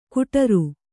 ♪ kuṭaru